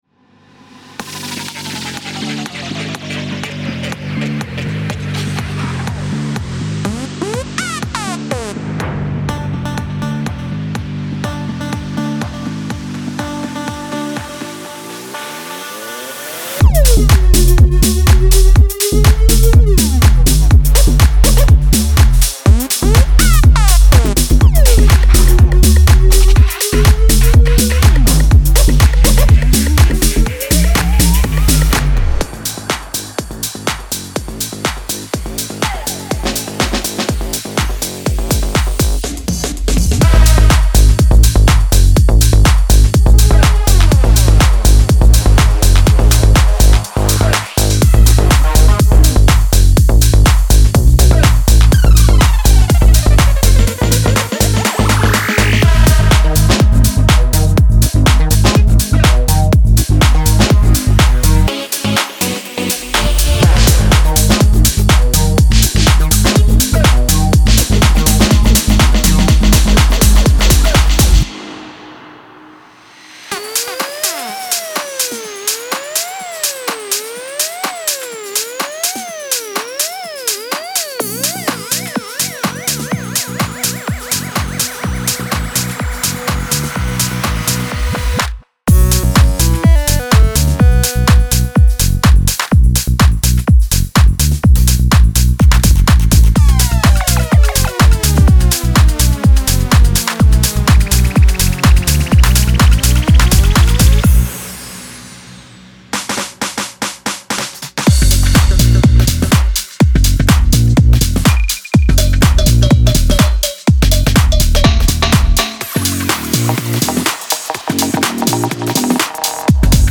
Tech House Collection
A smooth collection of 100 Serum presets featuring groovy basses, unique keys, signature leads, crisp plucks and spicy FX.